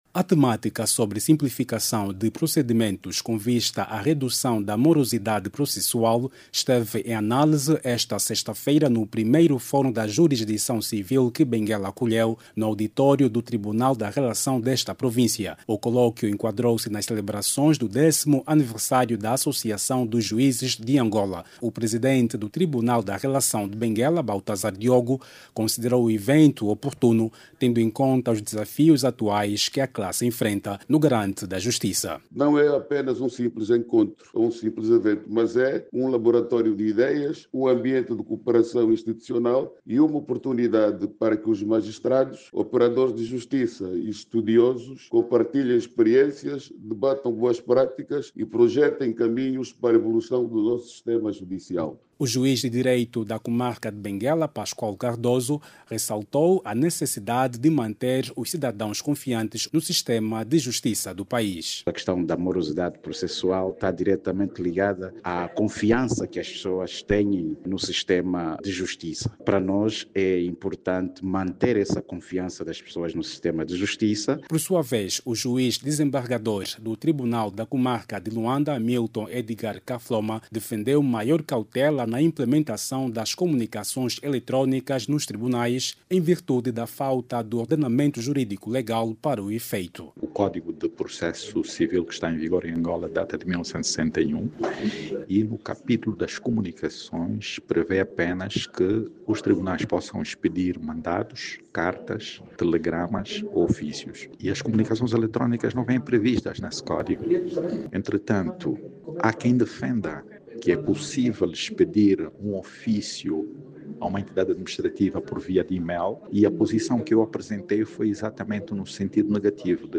A província Benguela, acolheu, ontem, sexta-feira(12), o primeiro Fórum da Jurisdição Civil promovido pela Associação dos Juízes de Angola. Na ocasião, o Juiz da Comarca de Benguela, Pascoal Cardoso, defendeu que é necessário manter a confiança dos cidadãos no sistema de justiça. Por outro lado, o Juiz Desembargador da Comarca de Luanda, Milton Cassoma, defendeu que o uso de comunicações electrónicas nos processos civis não tem previsão legal. Ouça no áudio abaixo toda informação com a reportagem